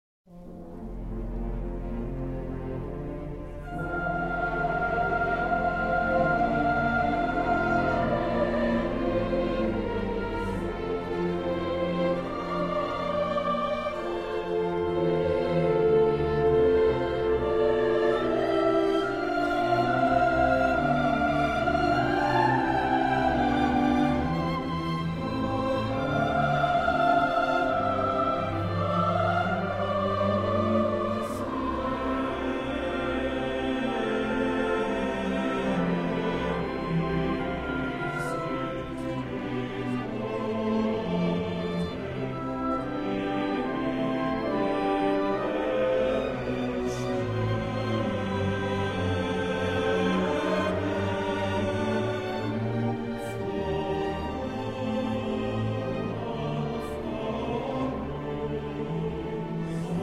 for chorus, orchestra, and a soprano and a baritone soloist.